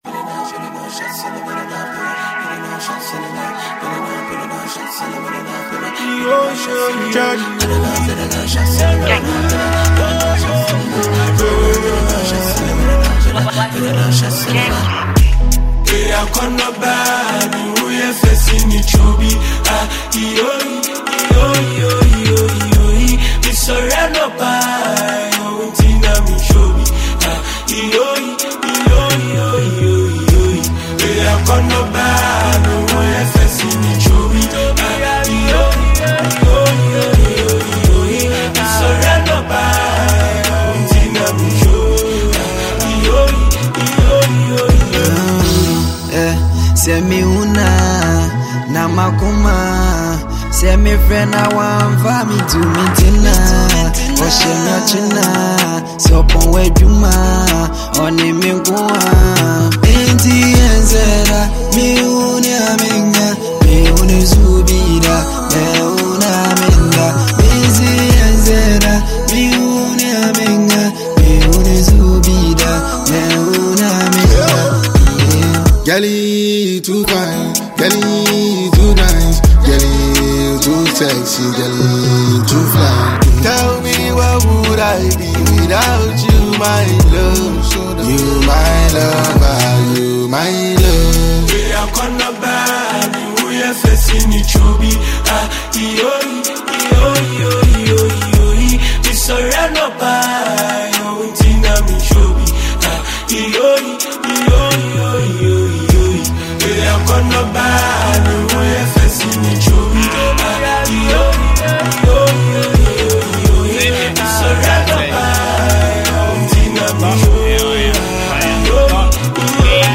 Ghana Music
blends street realism with catchy melodies and raw lyricism
hard-hitting Asakaa-style verses